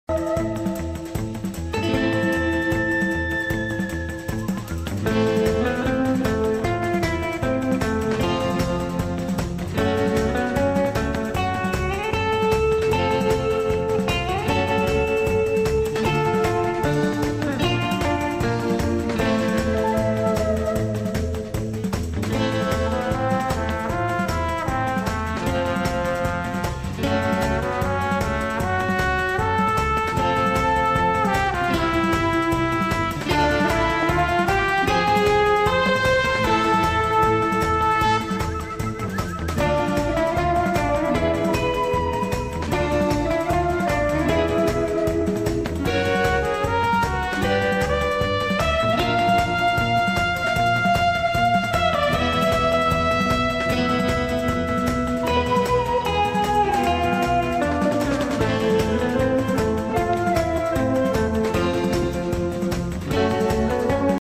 Утренний эфир с гостями.